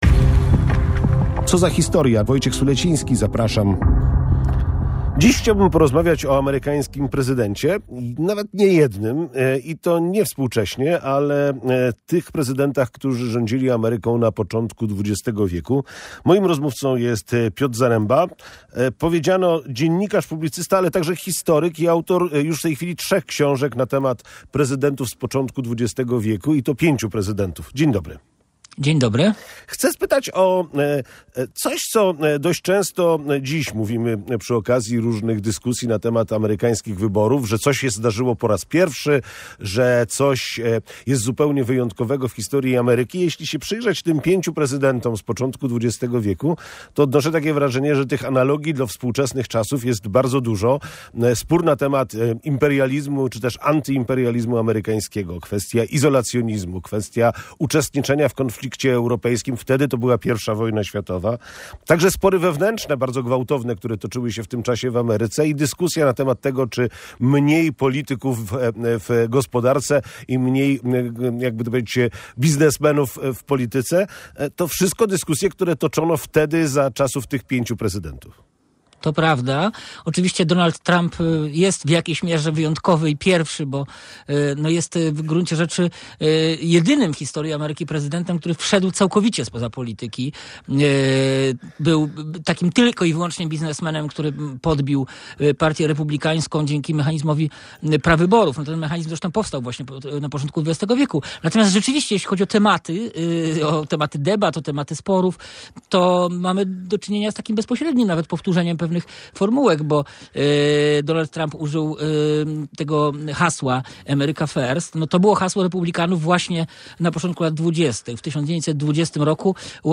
W dyskusji na temat obecnego kształtu Stanów Zjednoczonych jest sporo analogii z USA sprzed stu lat.